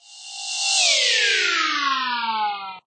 Index of /89/oc-3dshapes/sfx/
object_shrink.ogg